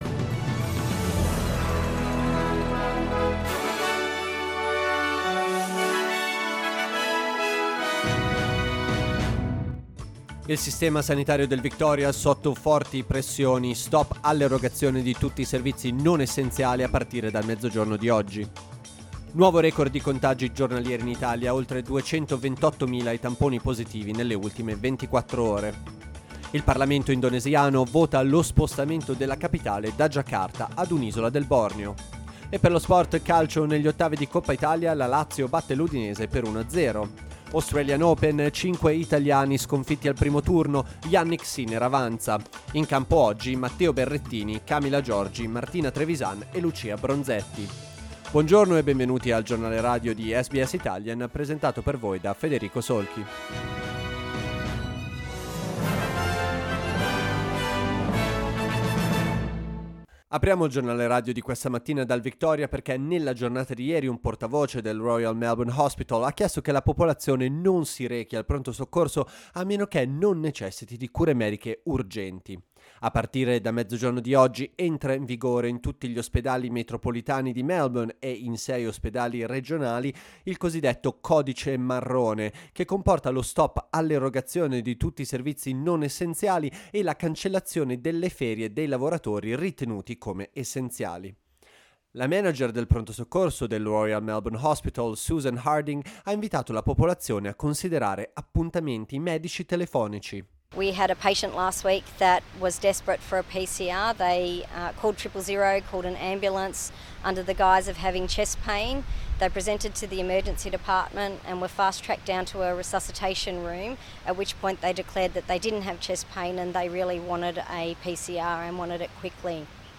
Giornale radio mercoledì 19 gennaio 2022
Il notiziario di SBS in italiano.